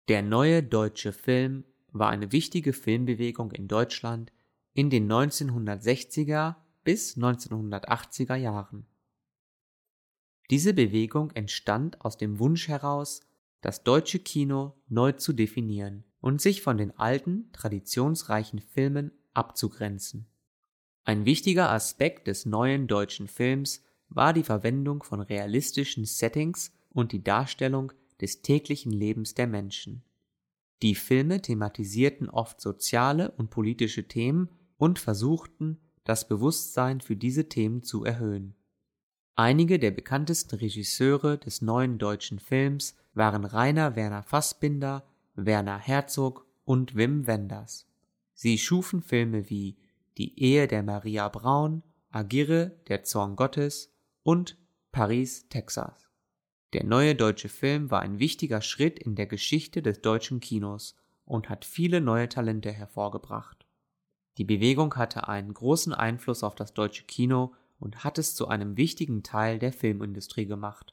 german-reading-new-german-cinema_zmwb1l.mp3